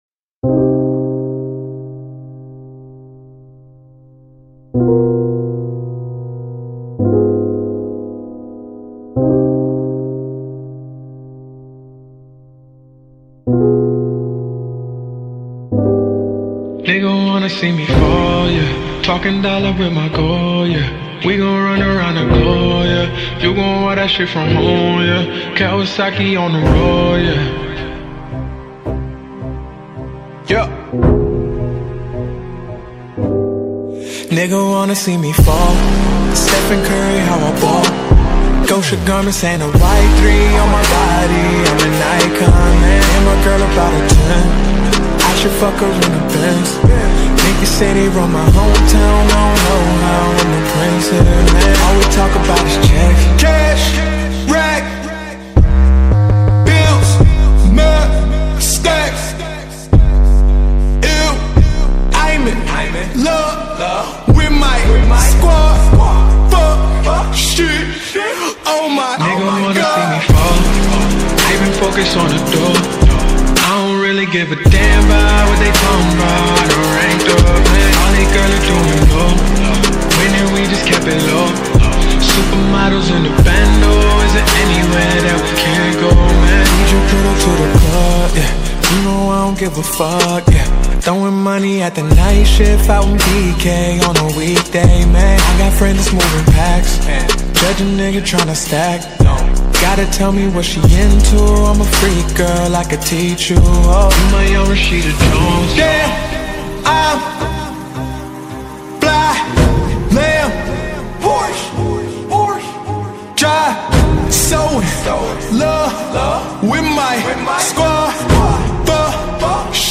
2024-06-17 14:22:52 Gênero: MPB Views